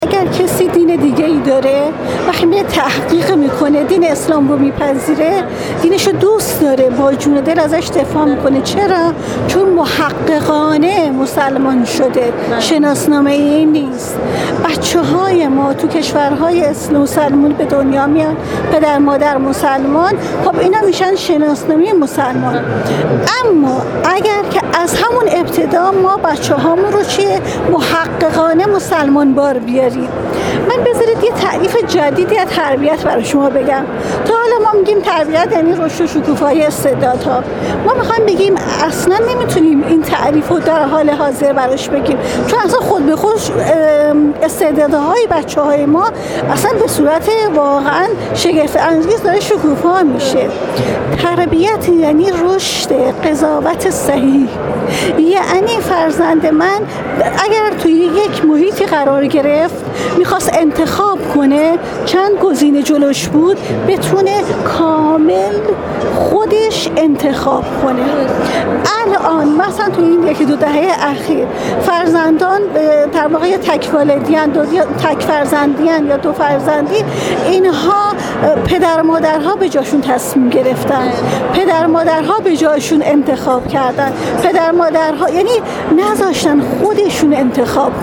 ، در گفت‌وگو با ایکنا، با اشاره به اهمیت تربیت دینی در خانواده‌ها اظهار کرد